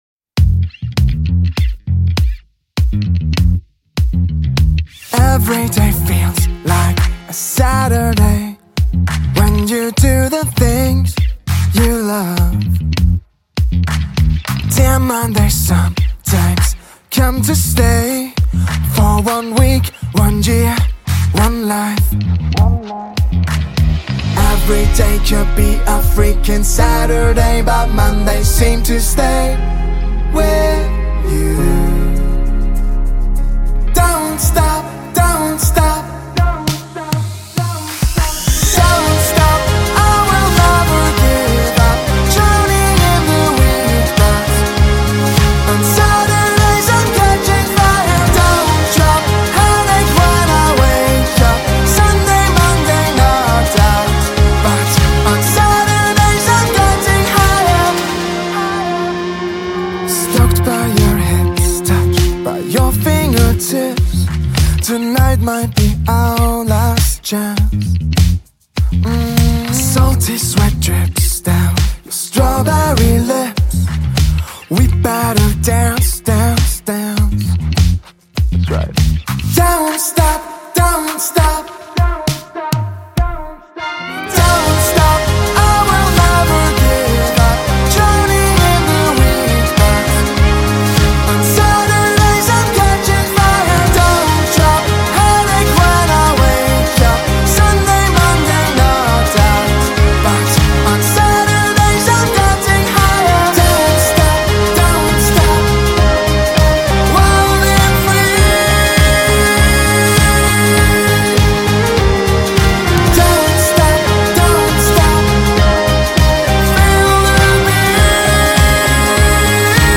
Rock und Pop